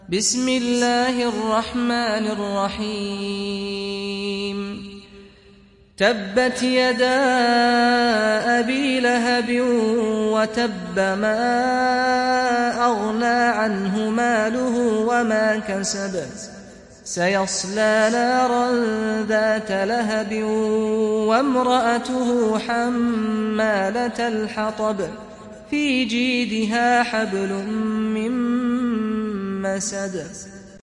دانلود سوره المسد mp3 سعد الغامدي روایت حفص از عاصم, قرآن را دانلود کنید و گوش کن mp3 ، لینک مستقیم کامل